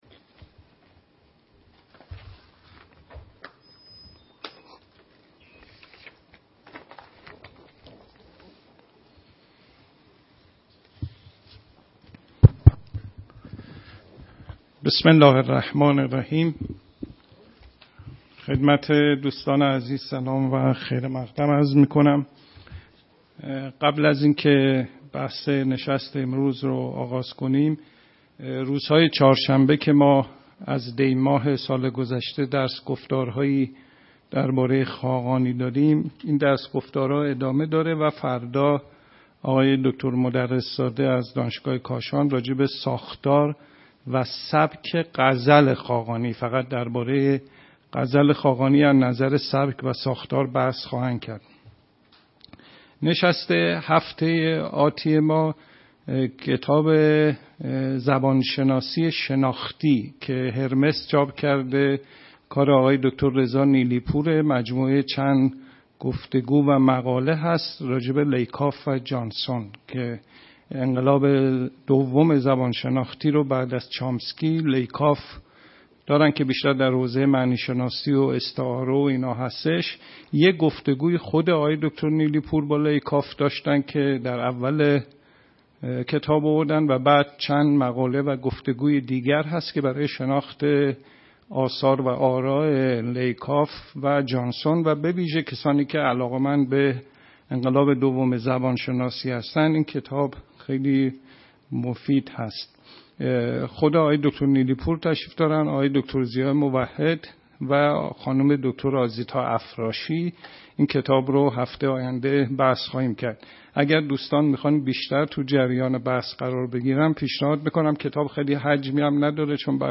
صوت/ نشست نقد و بررسی کتاب «بنیاد آموزه‌ فراگیر دانش» (1)؛
است که در مؤسسه‌ی شهر کتاب برگزار شد.